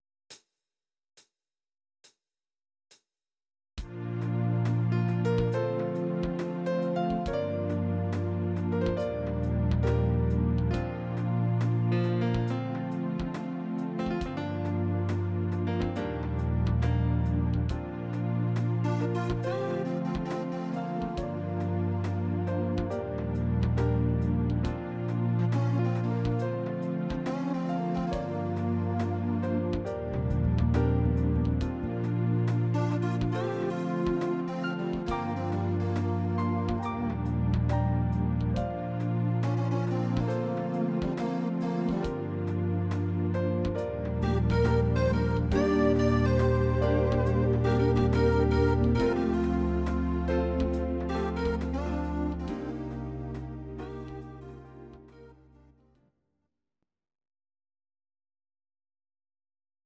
Audio Recordings based on Midi-files
Pop, 1990s